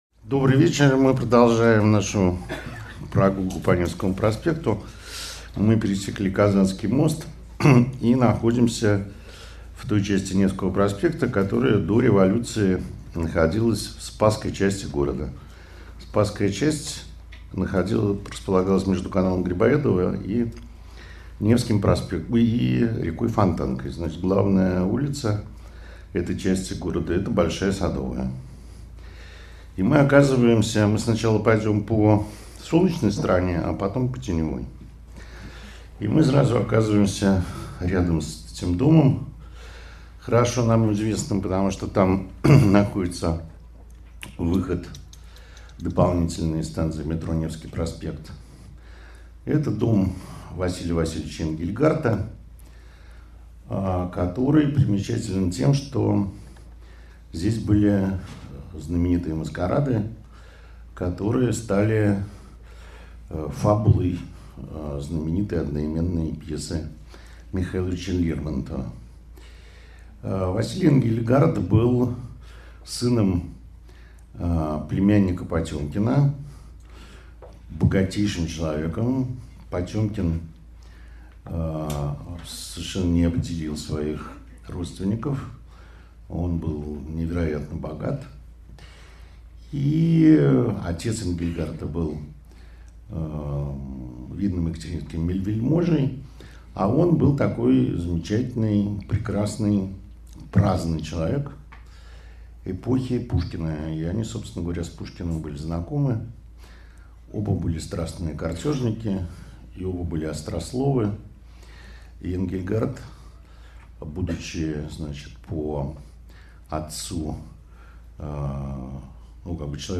Аудиокнига Спасская часть | Библиотека аудиокниг